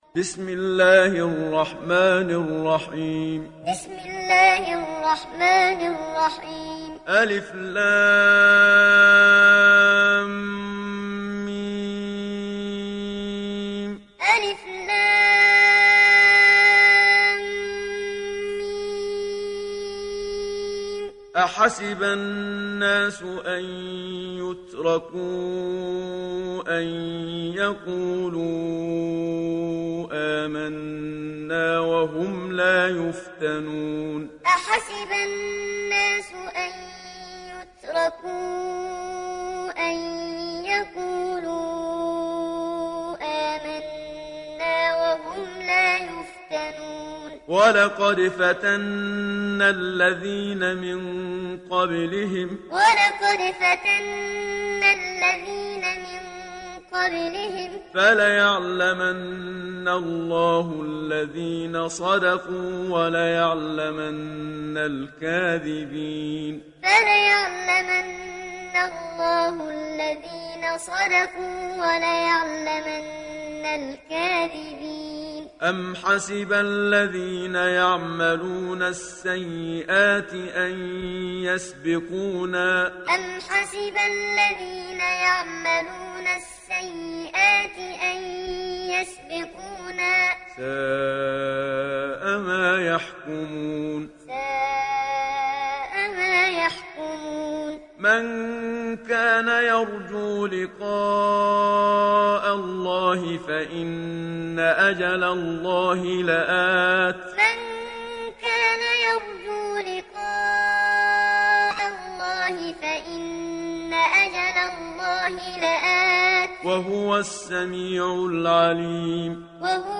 İndir Ankebut Suresi Muhammad Siddiq Minshawi Muallim